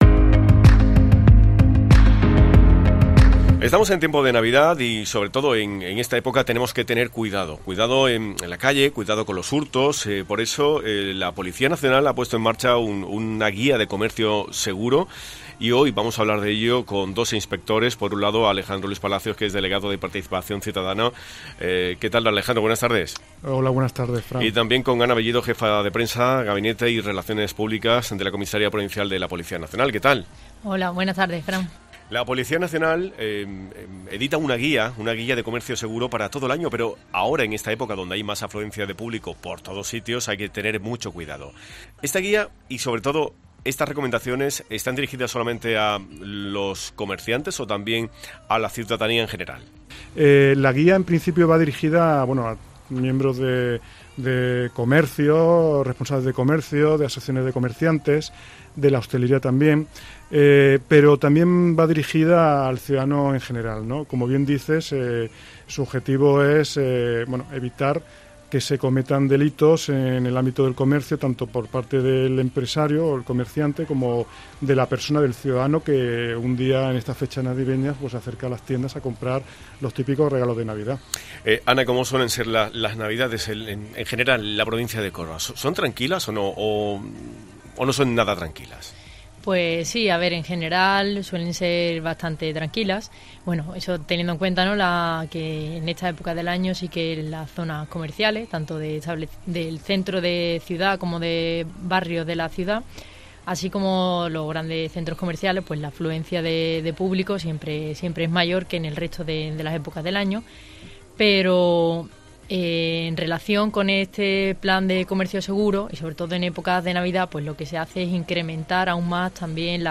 La Policía Nacional explica en COPE su "Guía de comercio seguro"